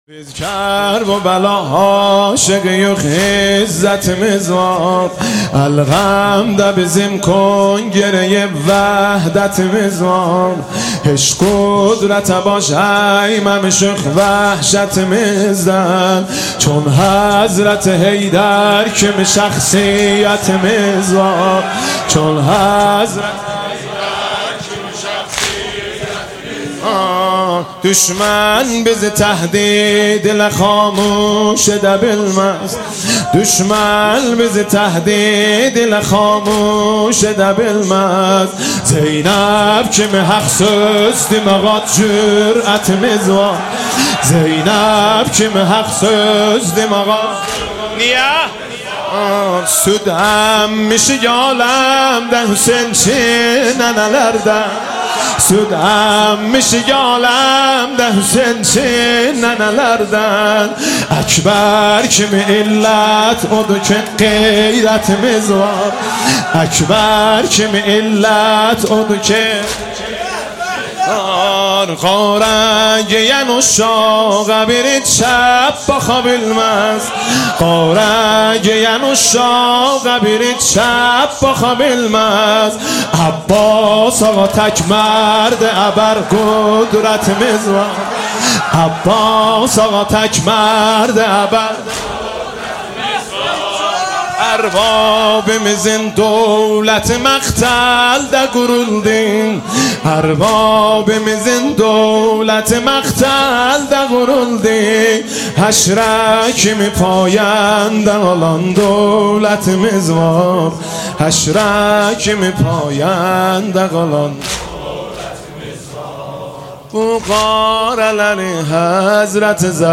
دانلود مداحی جدید حاج مهدی رسولی شب اول فاطمیه دوم 25 دیماه 1399 هیئت ثارالله زنجان